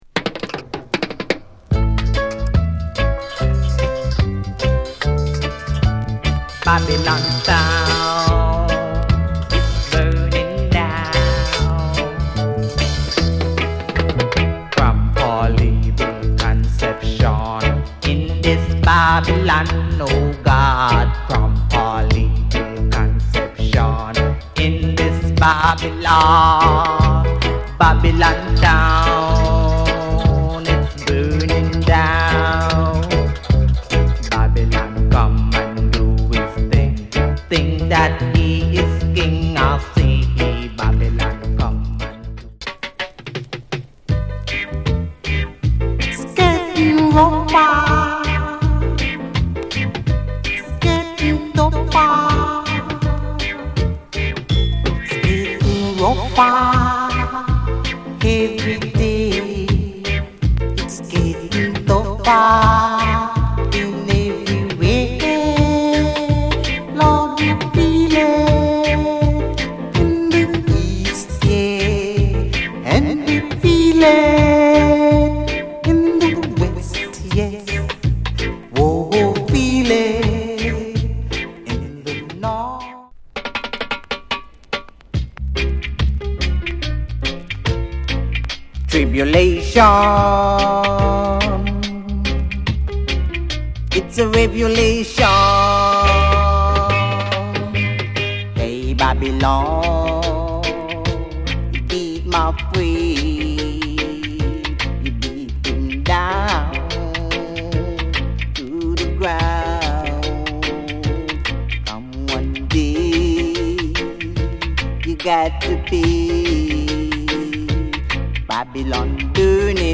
DUB
ROOTS